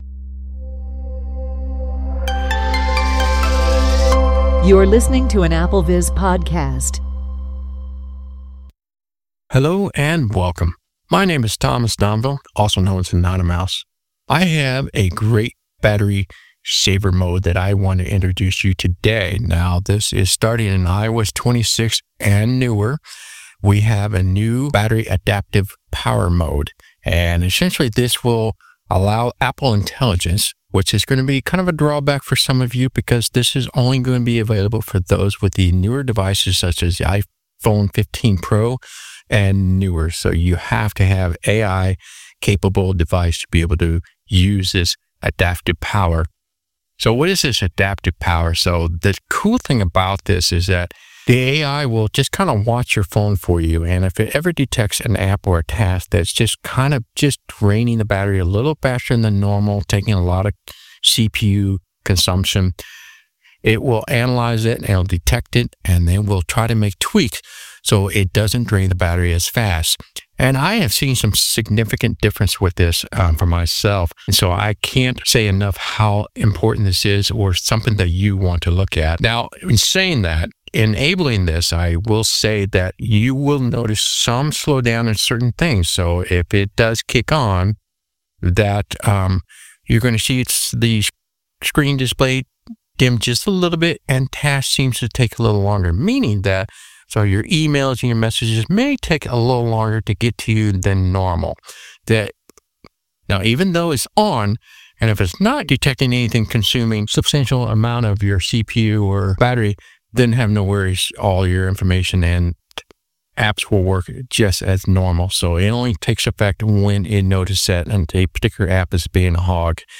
VoiceOver tips (from the demo)
Walk-through